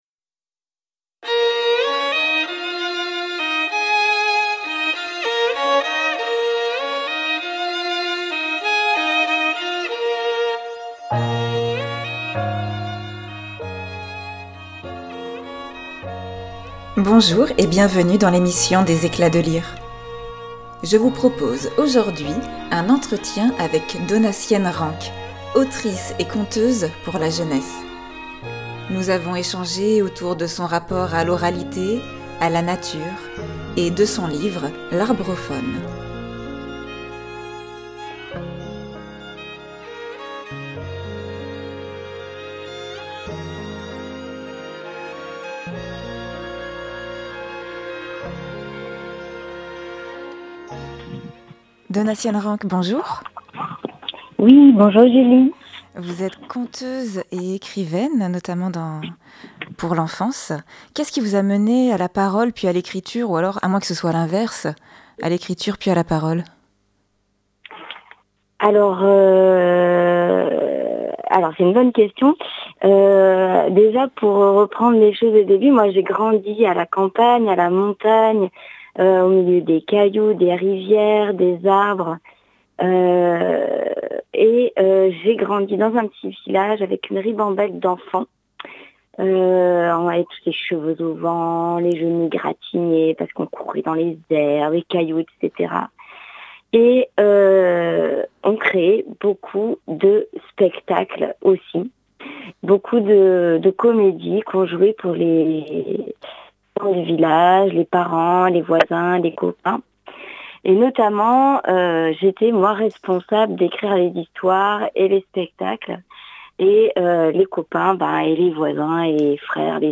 ÉMISSION RADIO
Des interviews d'auteur(e)s, des lectures, des échanges sur la littérature jeunesse...